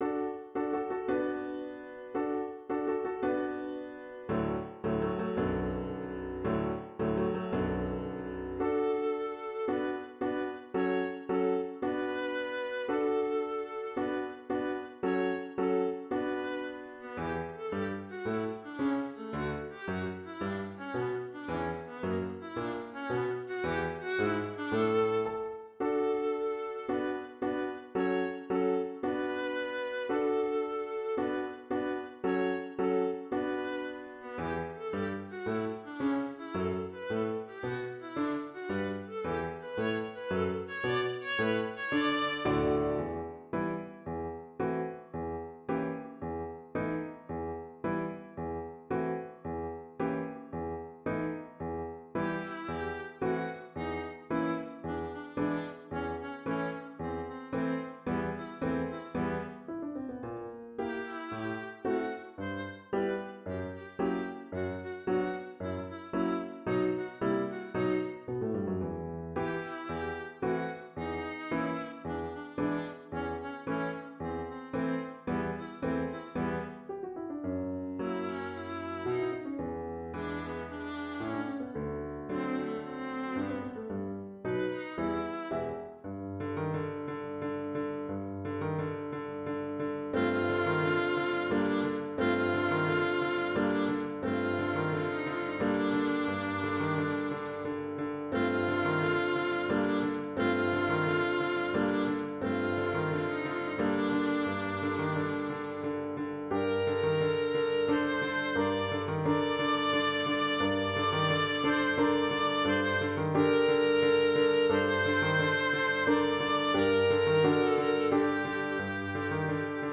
Violin and piano duet